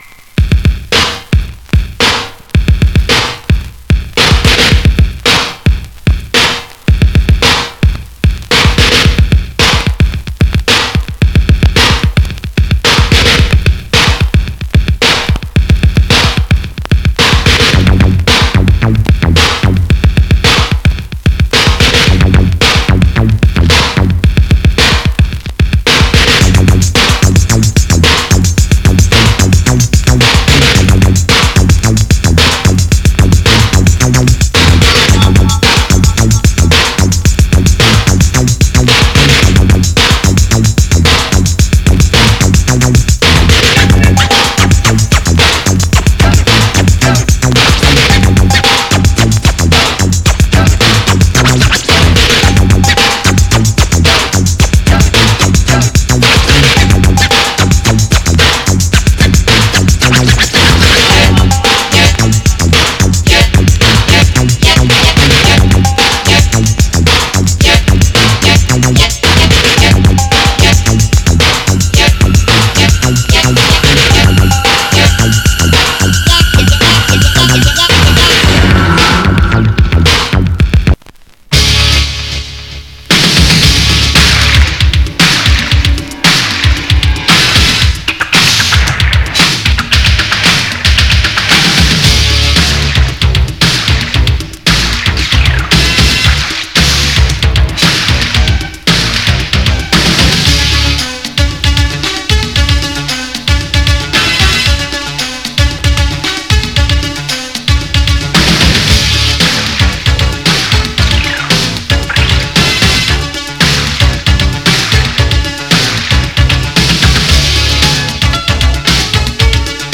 Genre:            Electronic, Funk / Soul
Style:              Boogie